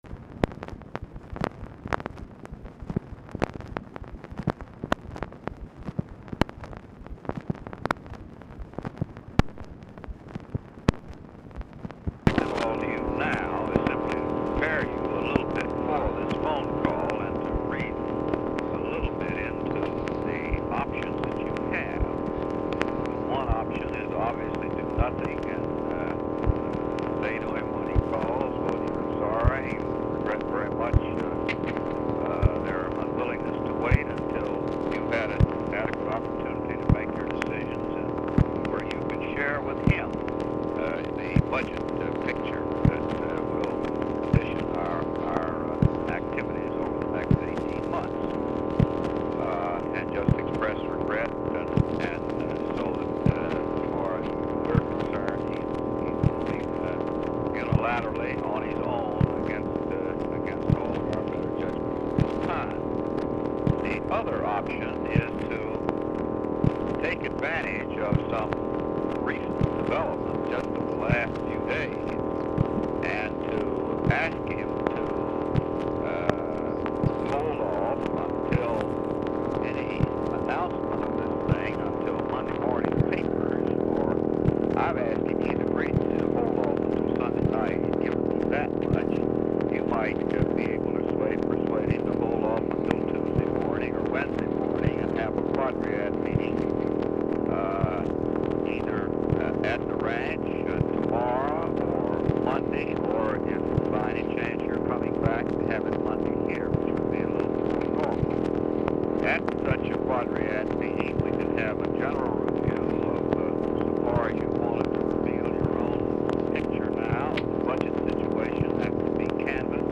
Telephone conversation # 9309, sound recording, LBJ and HENRY "JOE" FOWLER, 12/3/1965, 12:36PM
POOR SOUND QUALITY; LOUD BACKGROUND HUM; RECORDING STARTS AFTER CONVERSATION HAS BEGUN; CONTINUES ON NEXT RECORDING
Dictation belt
LBJ Ranch, near Stonewall, Texas